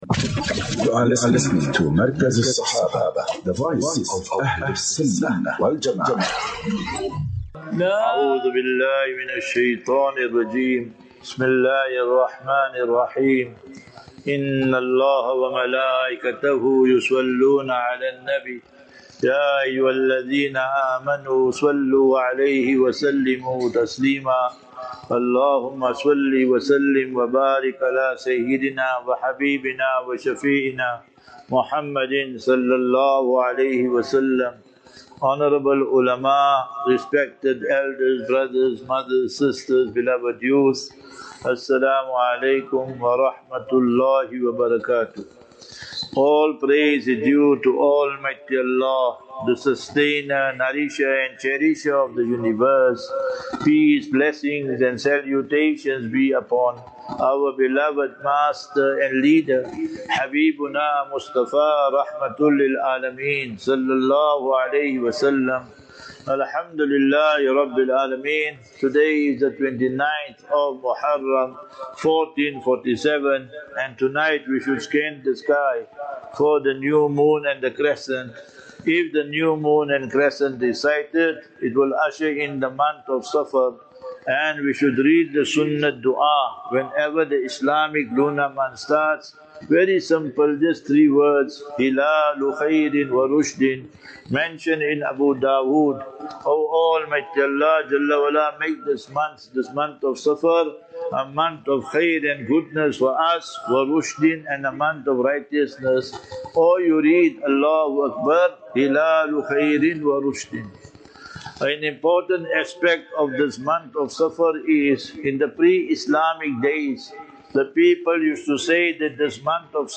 25 Jul 25 July 25 - Jumu,ah Lecture at Masjid Hamza (Erasmia) Pretoria.